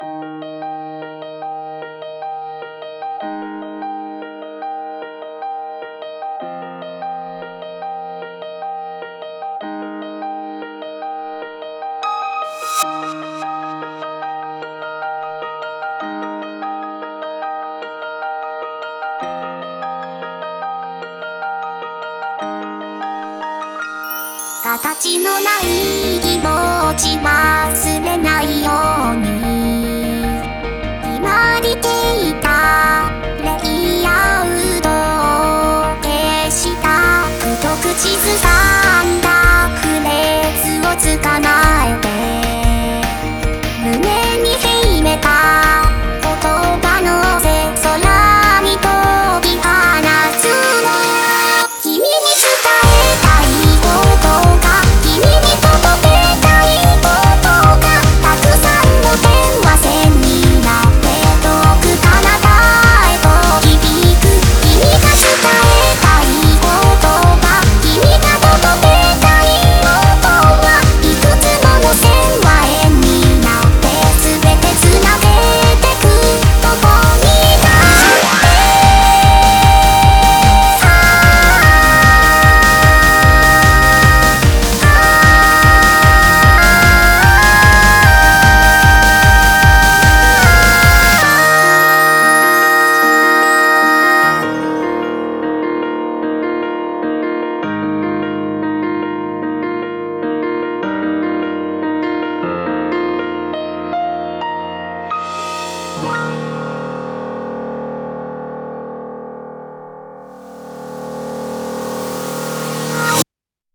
BPM75-150